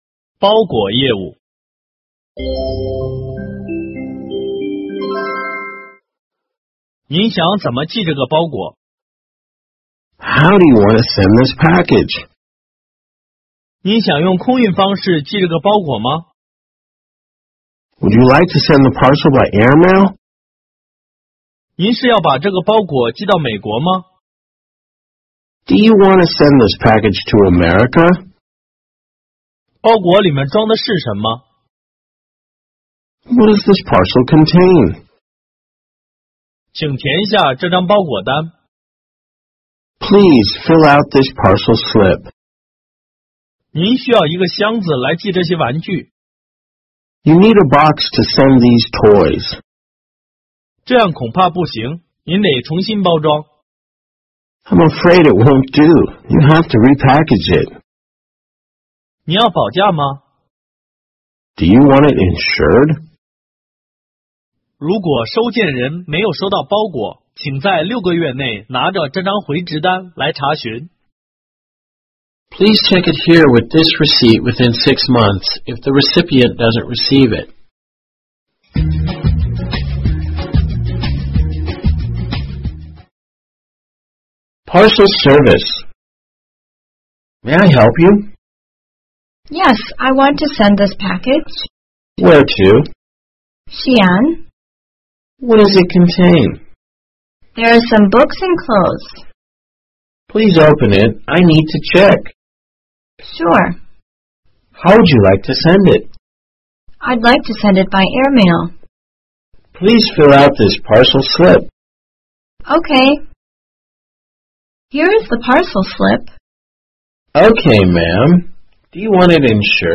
实用商务英语会话 场景90:包裹业务 听力文件下载—在线英语听力室